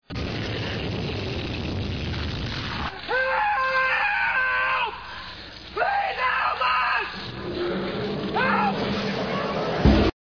These frightening sounds in the background are NOTHING compared to what you will experience in Hell.